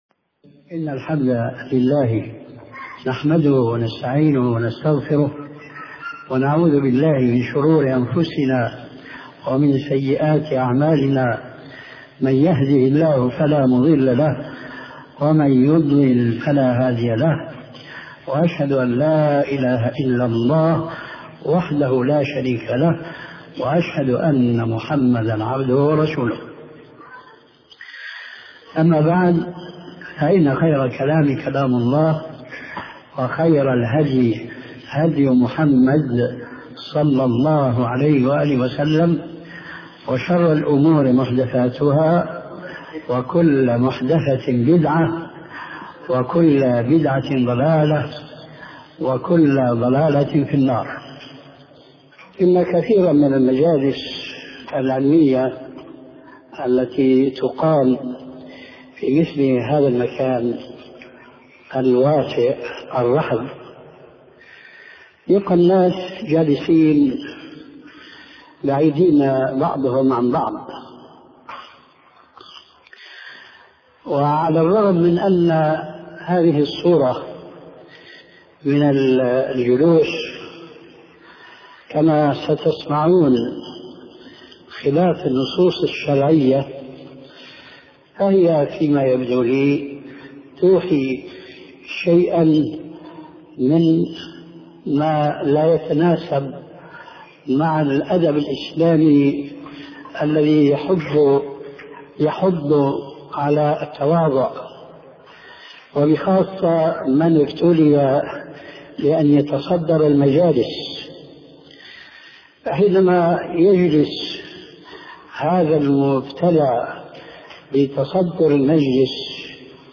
شبكة المعرفة الإسلامية | الدروس | آداب المجلس |محمد ناصر الدين الالباني